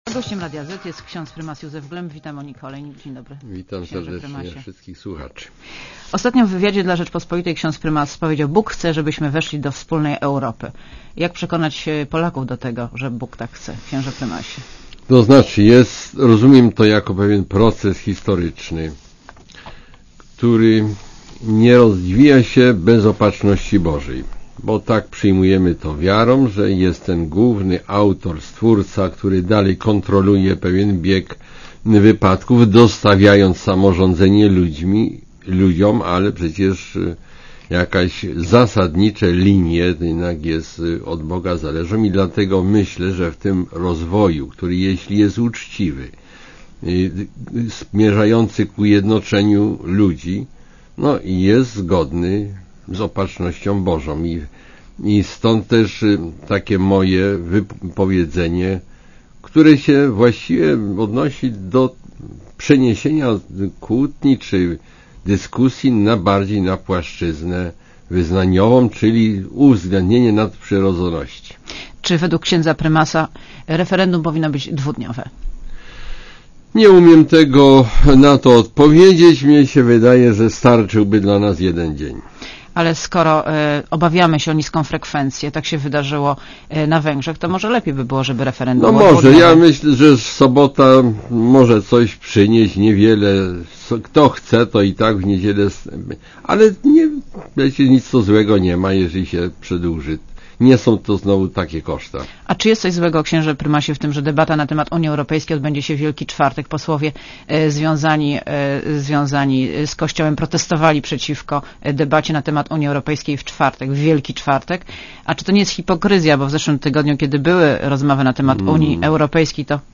Monika Olejnik rozmawia z prymasem Polski kardynałem Józefem Glempem